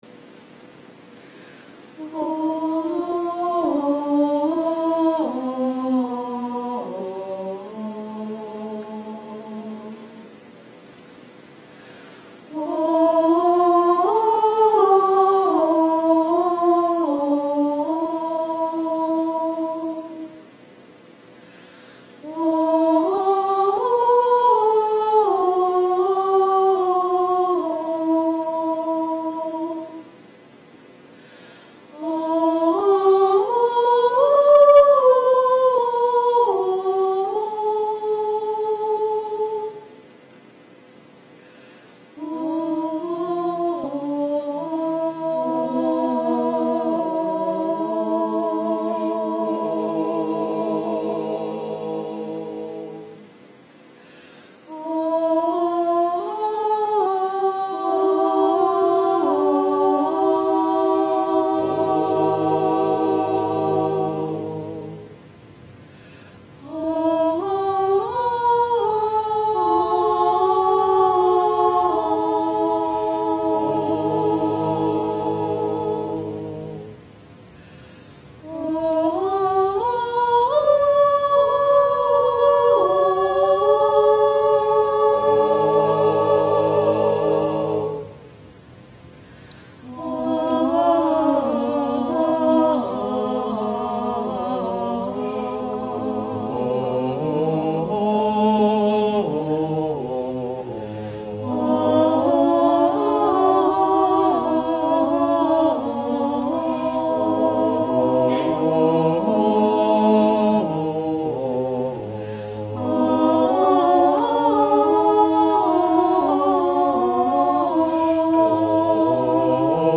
Meditation 1 (SATB a cappella)
Meditation 1 is a wordless meditative piece, consisting of increasingly textured repetitions of a theme reminiscent of Gregorian chant.
The recording conditions were not the best, but you should at least get an idea of what the piece sounds like.